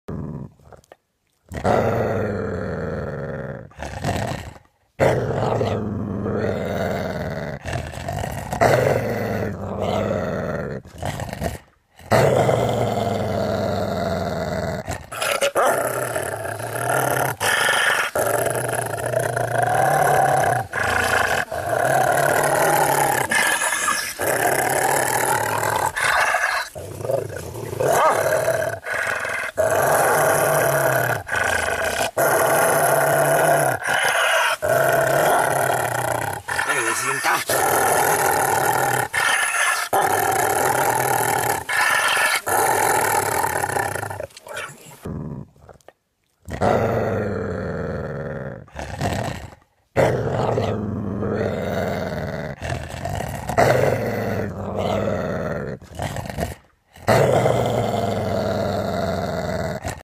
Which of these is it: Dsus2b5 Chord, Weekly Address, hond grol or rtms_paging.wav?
hond grol